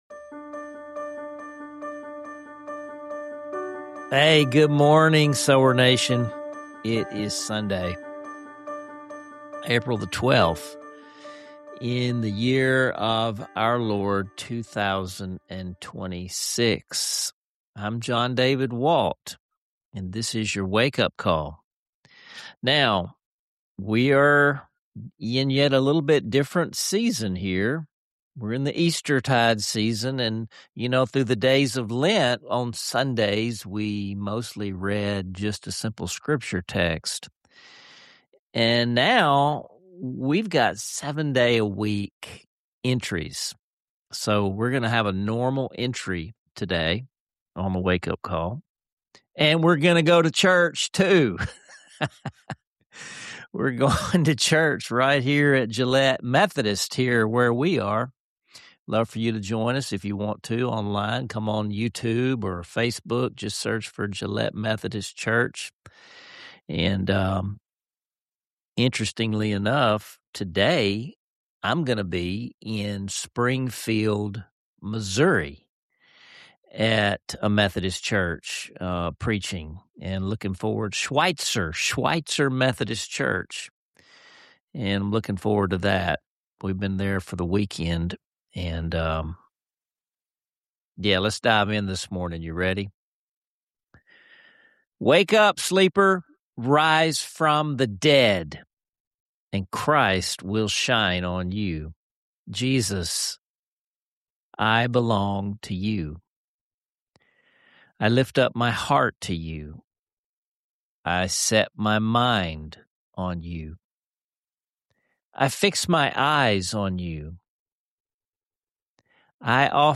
Tune in for a blend of heartfelt encouragement, real-world spiritual practices, and soul-stirring worship.